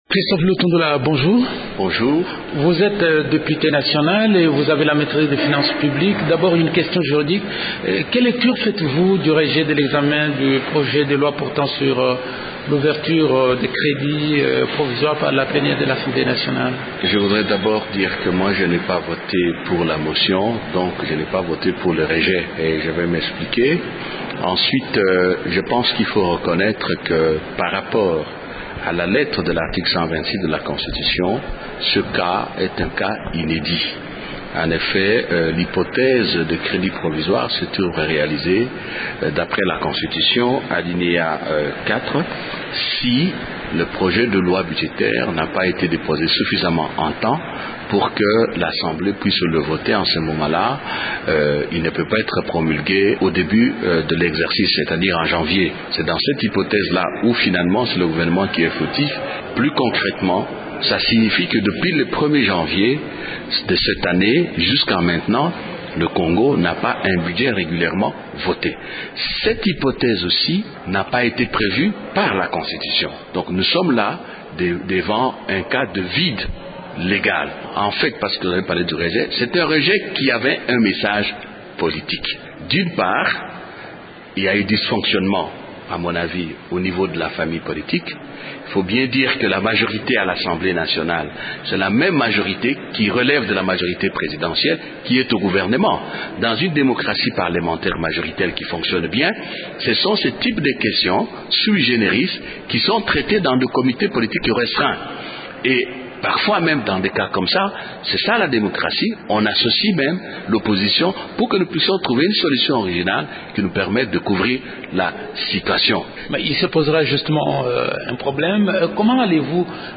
Le rejet du projet sur l’ouverture des crédits provisoires, l’augmentation de la solde des policiers et des militaires dans le budget 2010 et la cacophonie observée au sein de la majorité sur la question du budget sont les principaux sujets abordés avec le député de la majorité Christophe Lutundula.